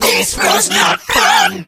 8bitvirus_hurt_vo_02.ogg